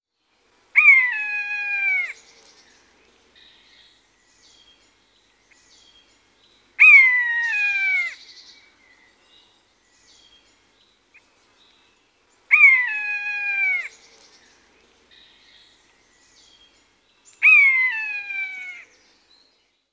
• beim Spazieren bewusst auf Naturgeräusche horchen (plätscherndes Wasser, Vogelgezwitscher, Frosch-Gequake, usw.).
Maeusebussard.mp3